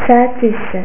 (pronuncia)   carne di maiale